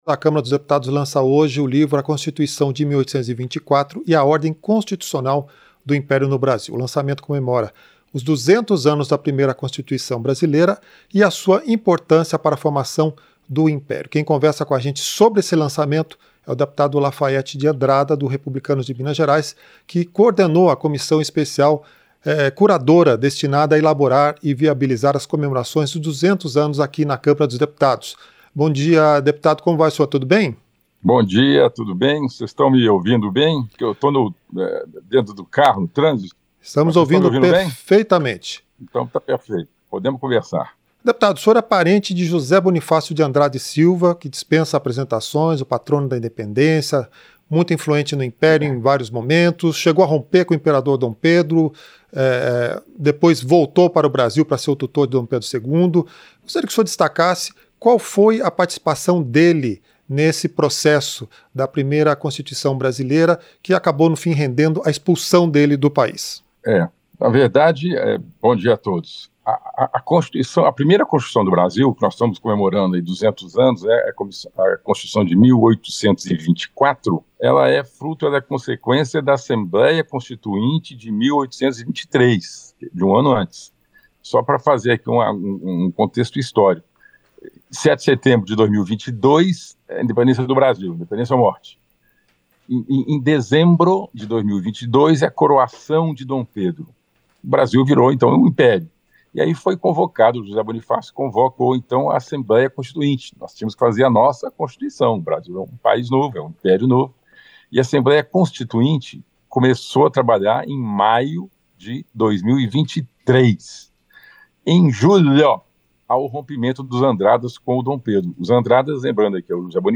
Entrevista - Dep. Lafayette de Andrada (Republicanos-MG)